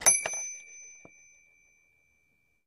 Baby Toy Bell, Single Ring